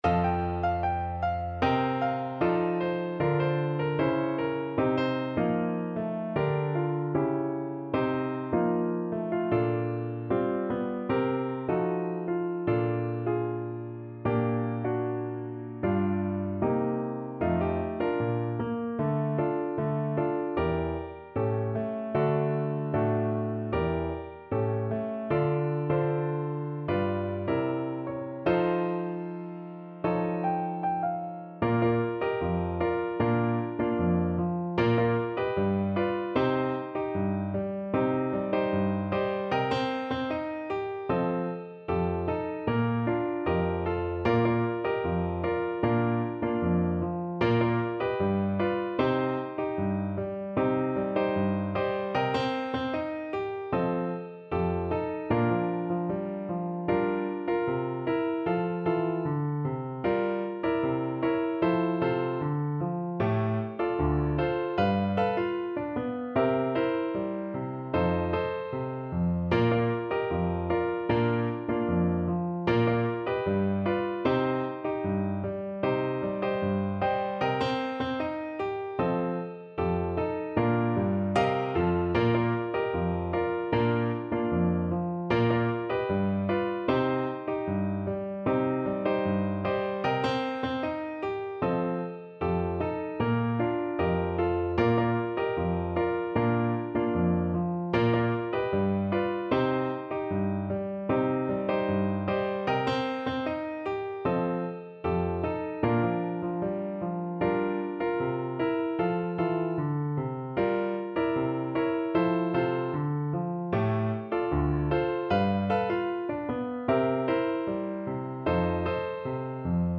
Pop (View more Pop Clarinet Music)